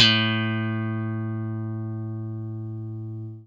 FENDRPLUCKAC.wav